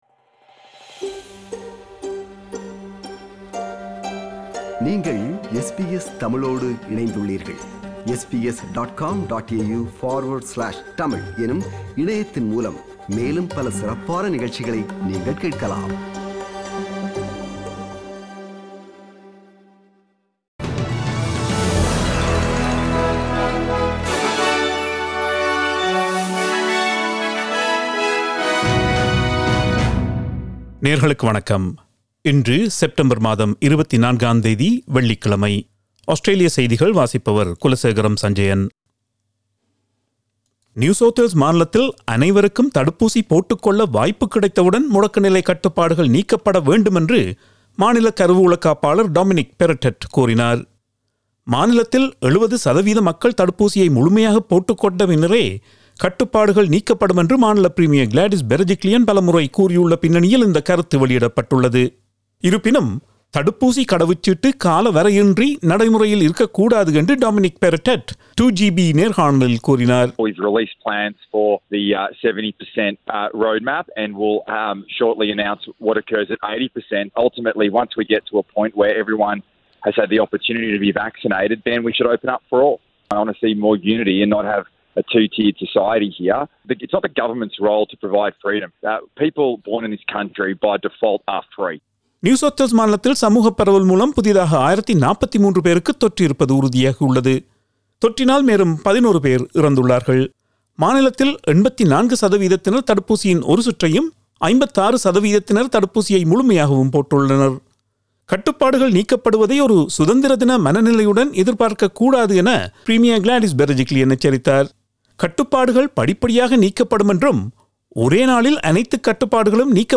Australian news bulletin for Friday 24 September 2021.